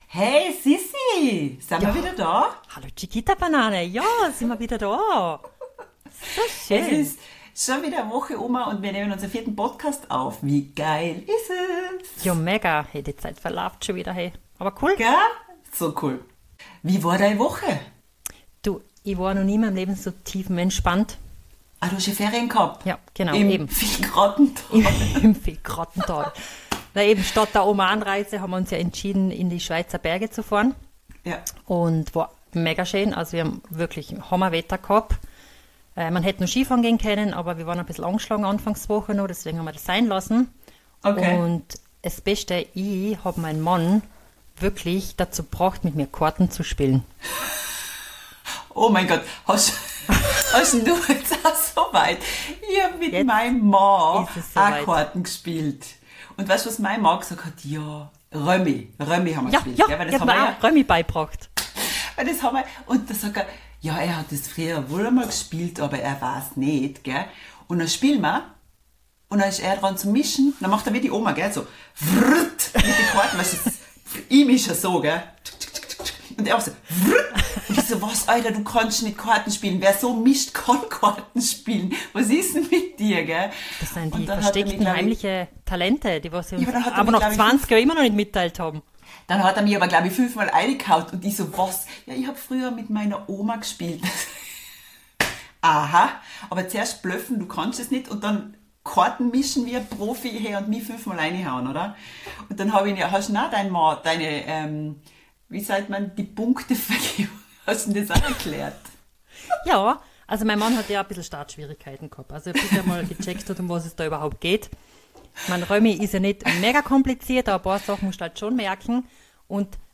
2 Schwestern aus Österreich die seit über 20 jähren in der Schweiz Leben. Weihnachten war toll mit der Familie, die Urlaube in Italien hatten in sich und die Italiener auch. zum Verhängnis wurde uns die Autowaschanlage das eine bis Heute nicht hin bekommt...